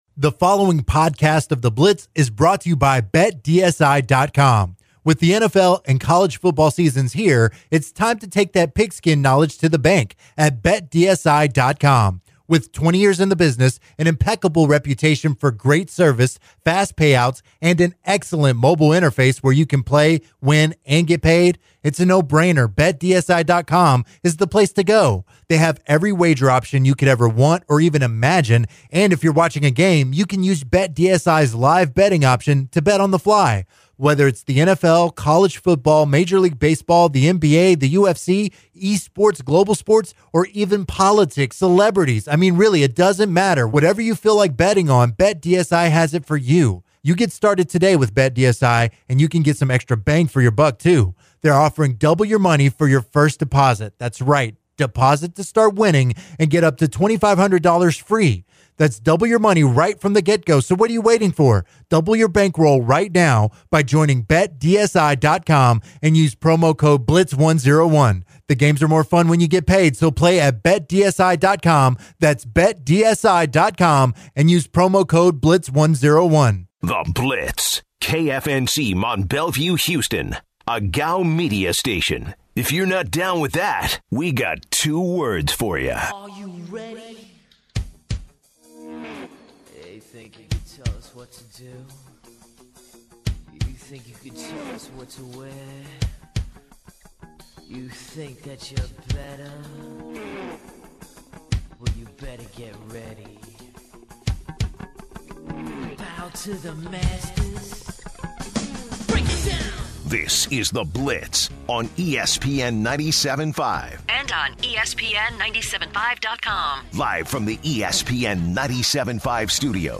many callers want to get in on the conversation as well.